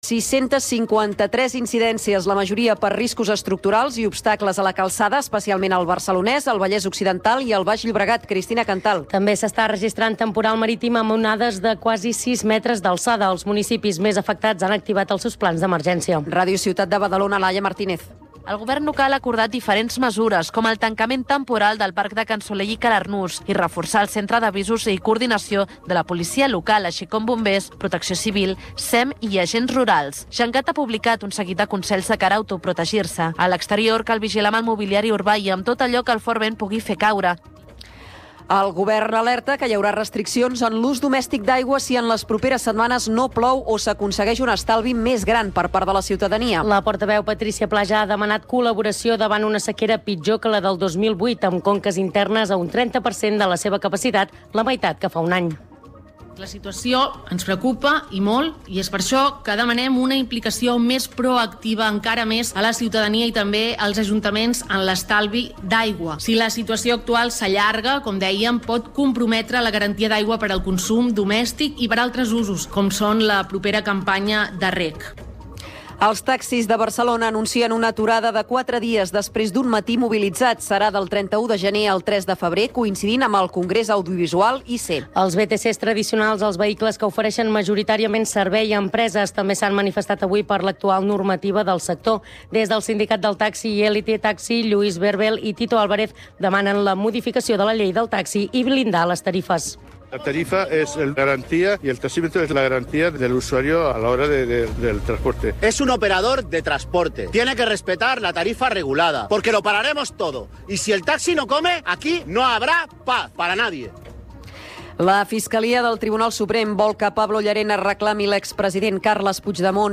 Informatius Notícies en xarxa (edició vespre)